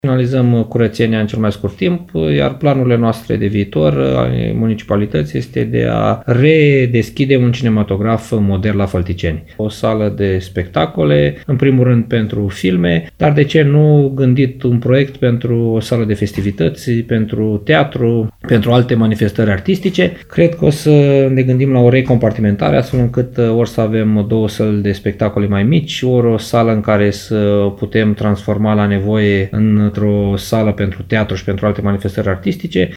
Primarul de Fălticeni, CĂTĂLIN COMAN, a declarat că cinematograful “Doina” va fi recompartimentat, dar că își va păstra destinația culturală.